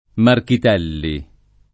[ markit $ lli ]